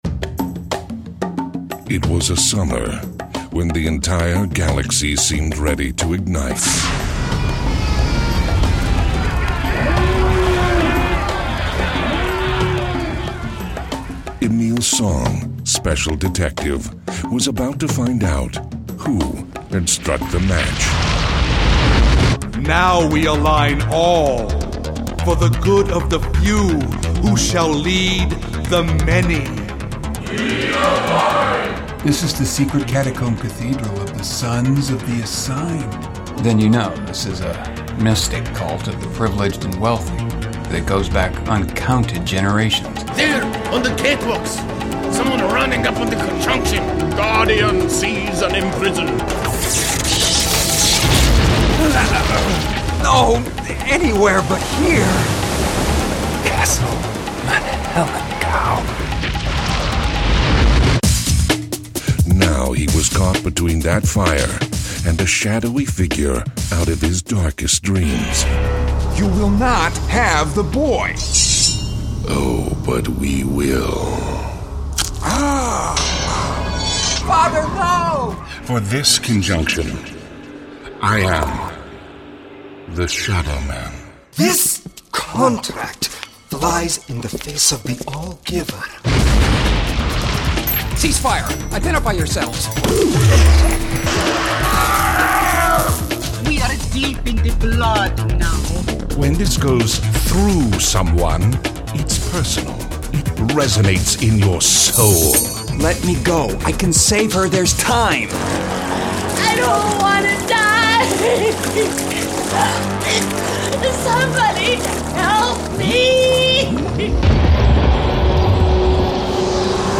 A RADIO SHOW FROM YET ANOTHER DIMENSION